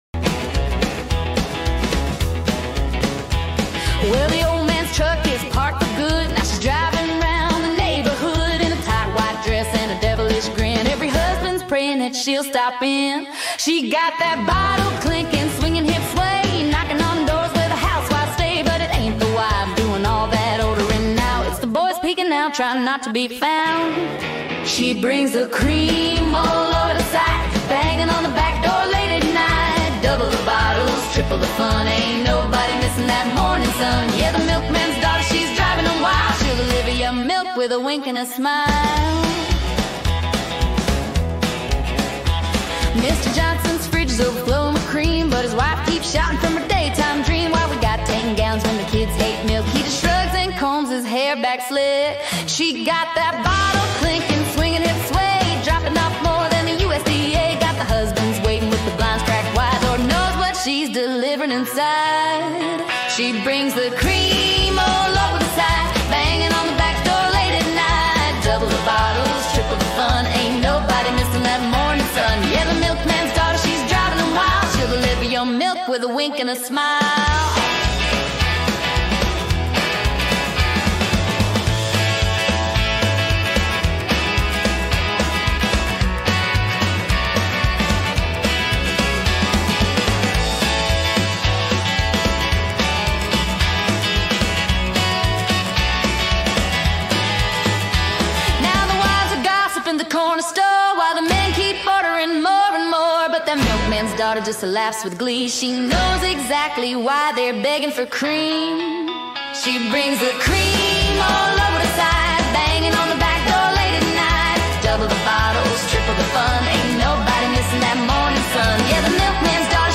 es un himno rockabilly descarado y con sabor retro